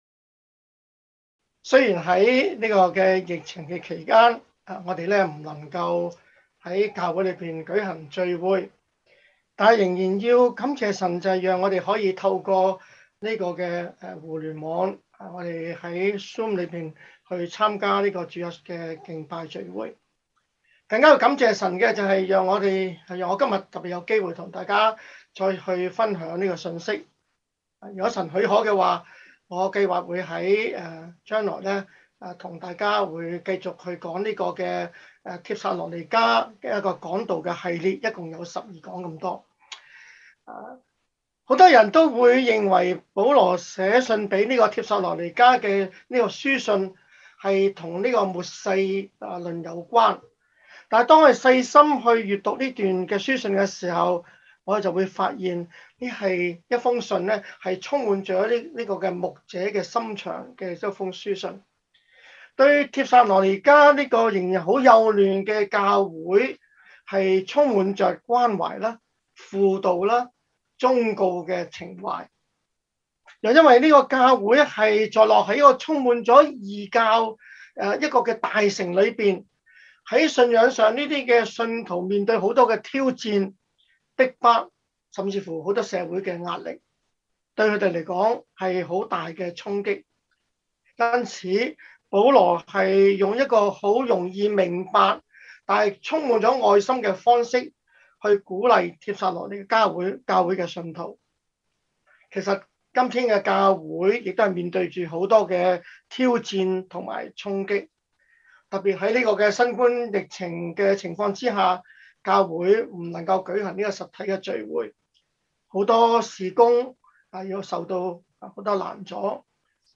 sermon0919.mp3